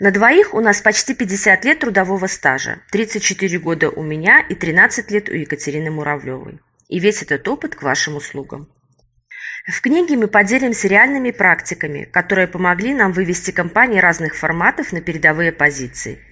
Аудиокнига Кадры решают… Кадры мешают | Библиотека аудиокниг